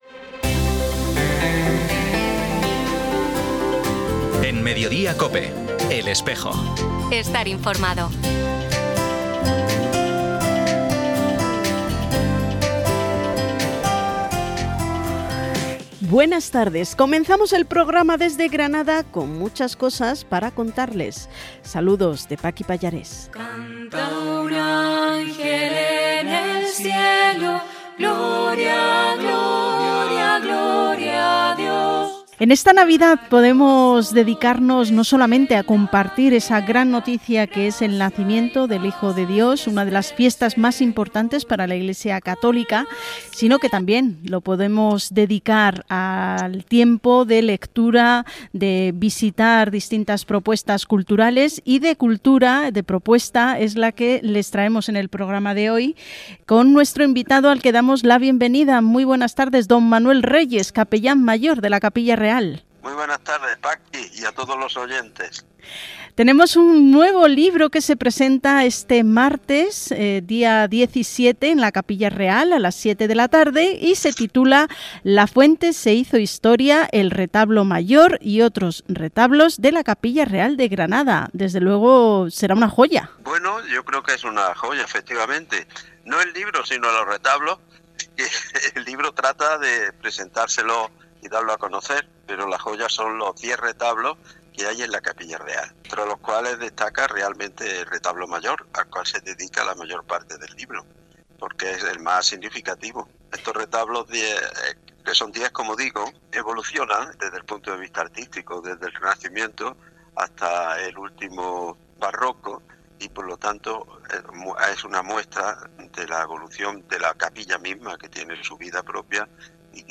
Emitido hoy 13 de diciembre en COPE Granada y COPE Motril.